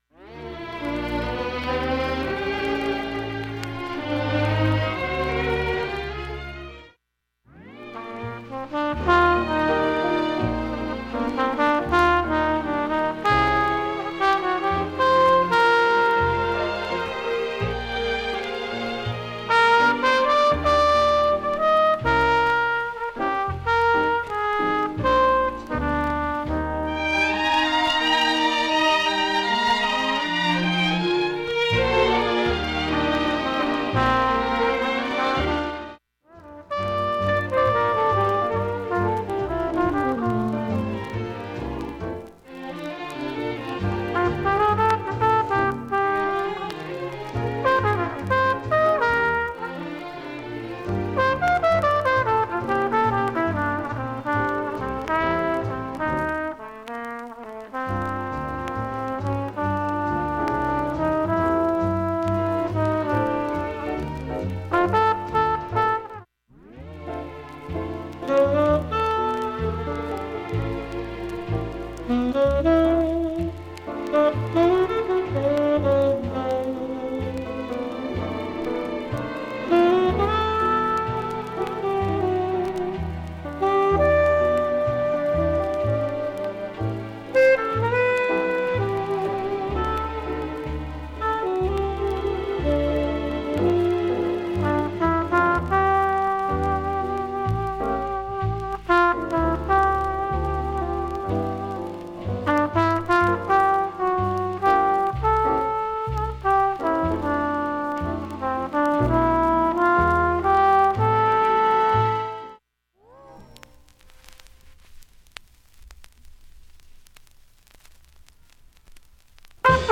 プツ聴こえるか不安なレベルが多いです。
チリ音もほとんど無くいい音質です。
ウエスト・コースト派のミュージシャン